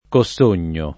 vai all'elenco alfabetico delle voci ingrandisci il carattere 100% rimpicciolisci il carattere stampa invia tramite posta elettronica codividi su Facebook Cossogno [ ko SS1 n’n’o ] top. (Piem.) — pn. loc. con -o- aperto